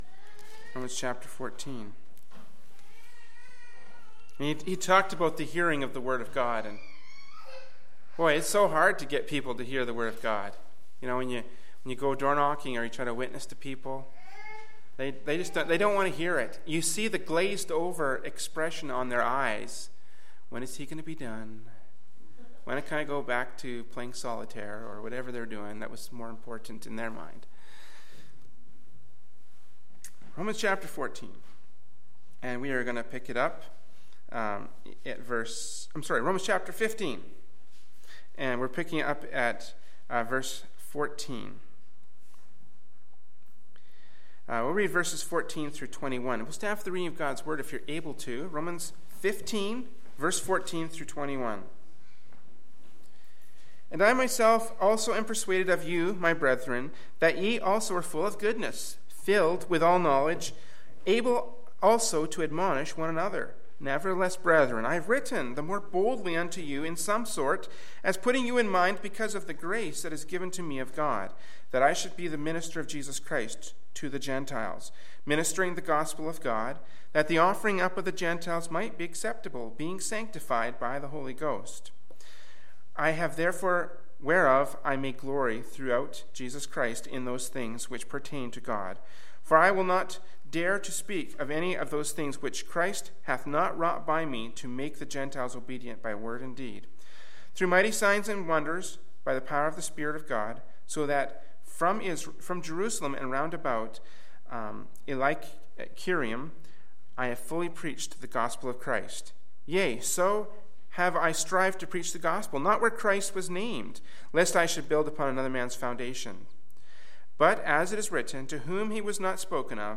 Kamloops, B.C. Canada
Adult Sunday School